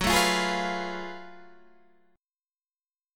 F#mM11 chord